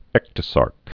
(ĕktə-särk)